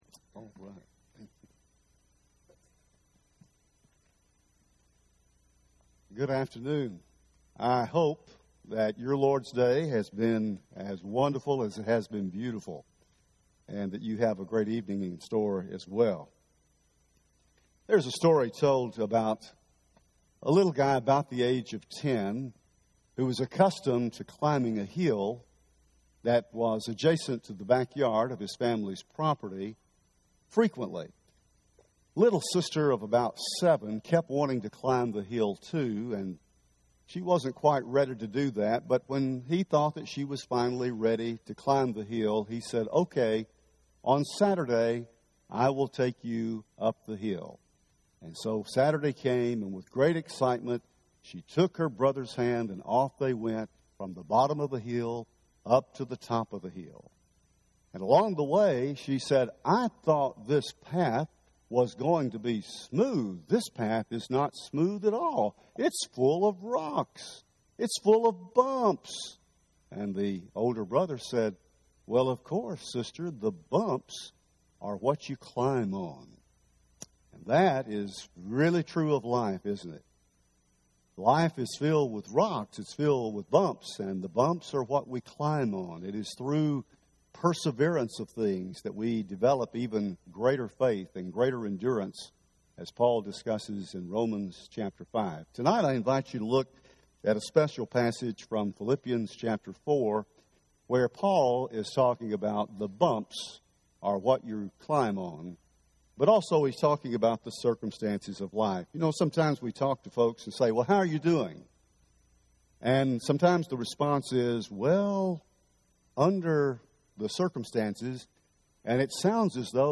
Under the Circumstances – Henderson, TN Church of Christ